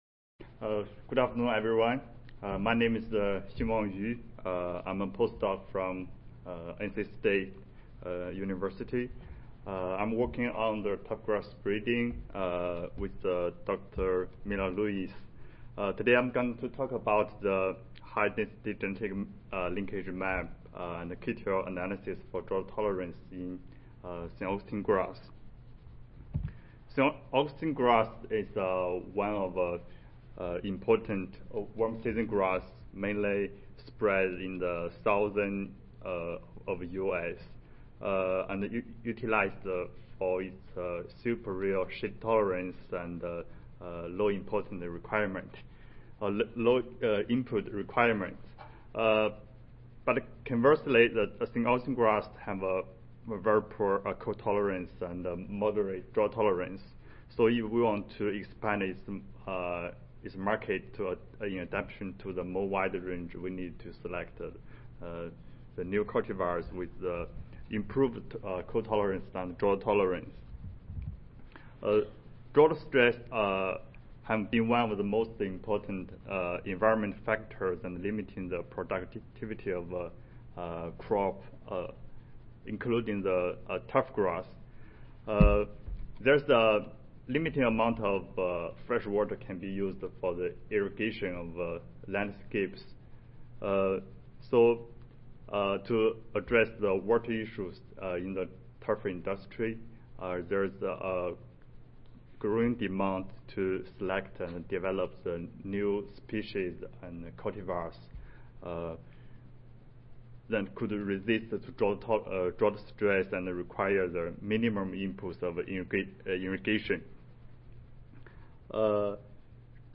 North Carolina State University Audio File Recorded Presentation